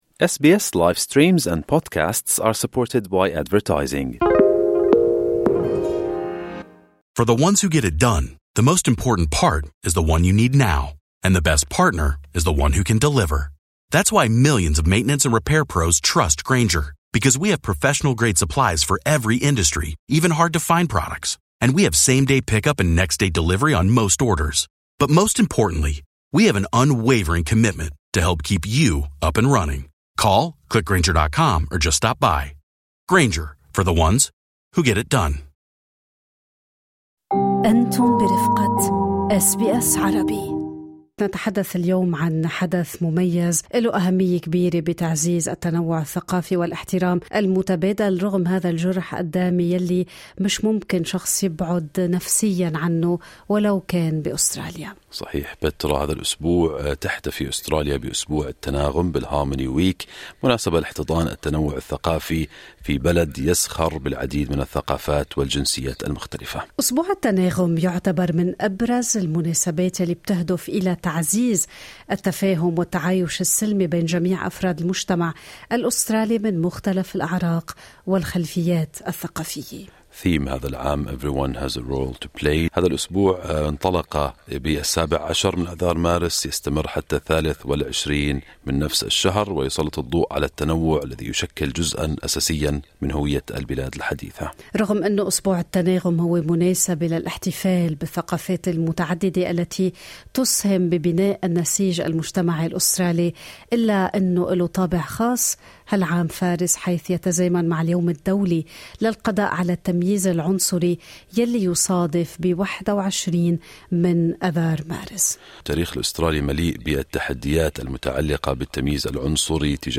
نظمت غرفة التجارة والصناعة العربية الأسترالية حفل إفطارها الرمضاني السنوي وتزامن الحدث مع أسبوع التناغم الثقافي في أستراليا.